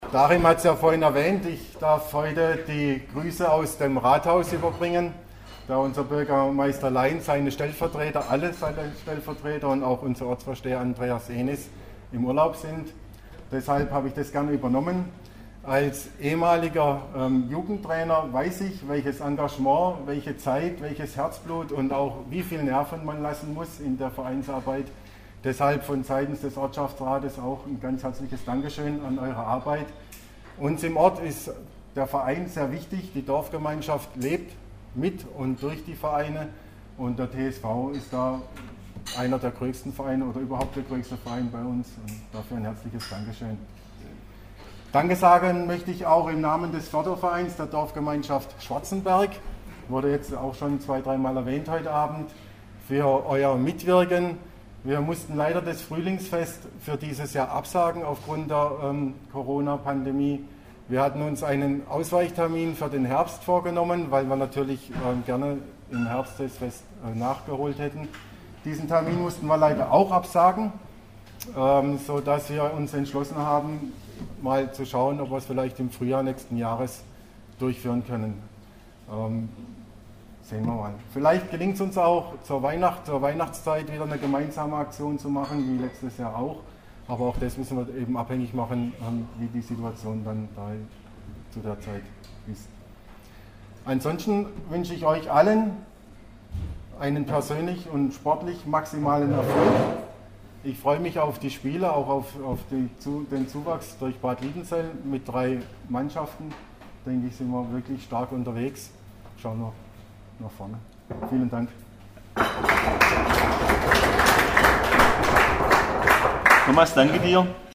Audiomitschnitt seines Grußworts: